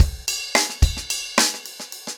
Framework-110BPM_1.3.wav